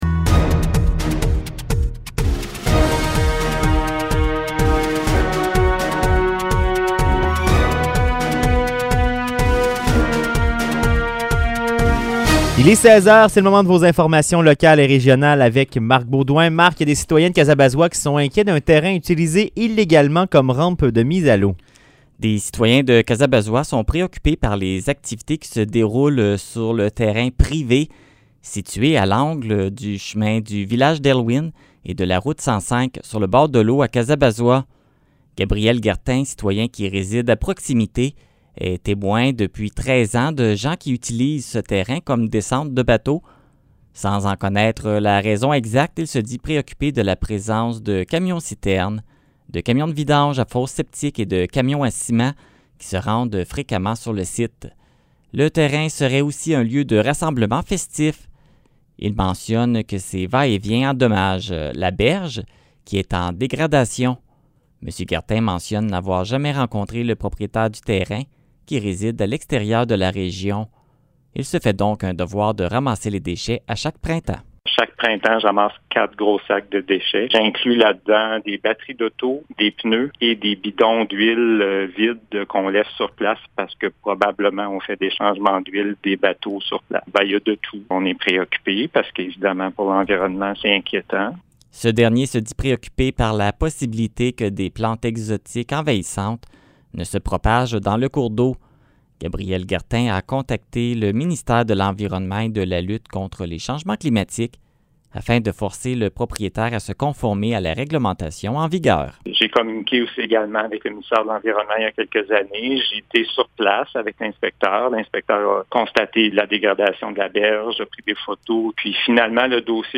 Nouvelles locales - 4 août 2021 - 16 h